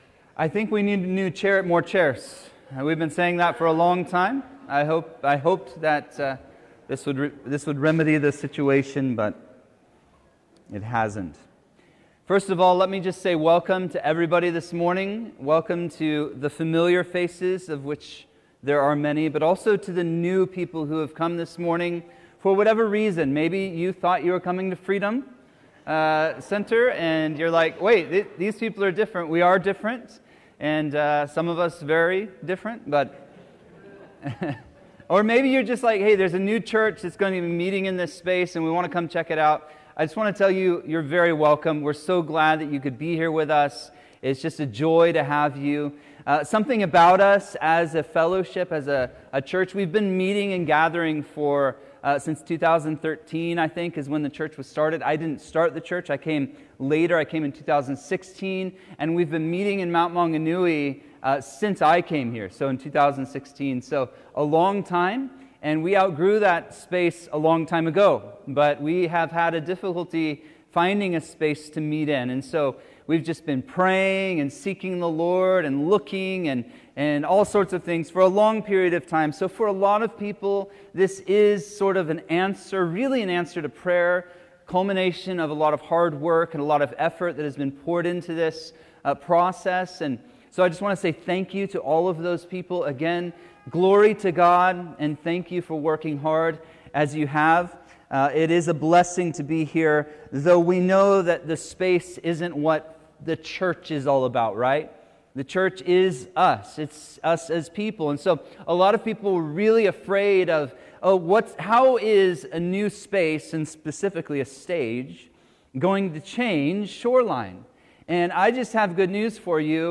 Sermons | Shoreline Calvary